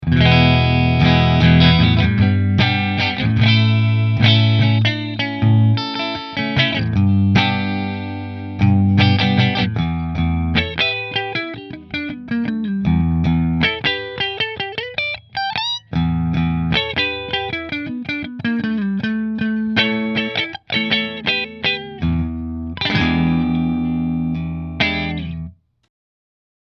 This bolt-on neck semihollow guitar is a tone machine!